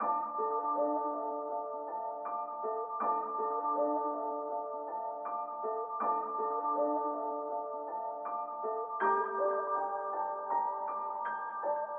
clvc_02_160bpm_dm_piano.mp3